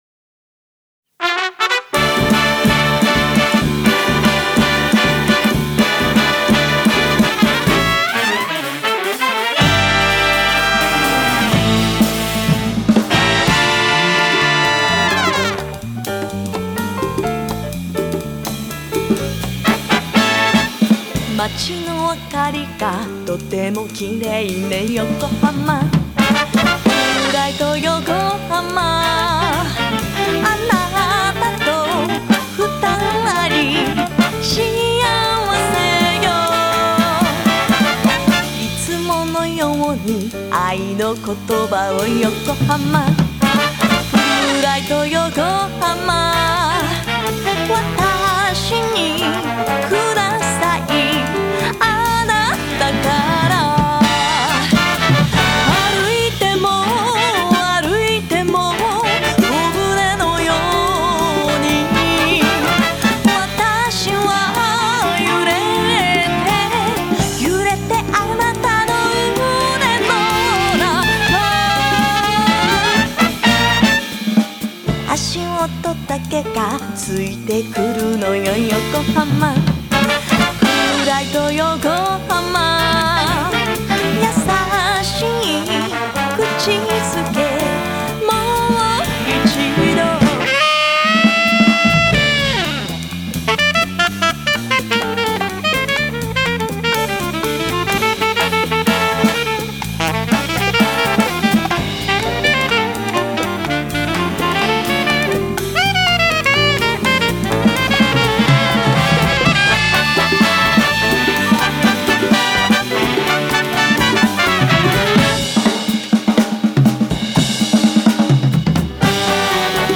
和ジャズ
総勢18名からなる本格派ガールズ・ビッグバンド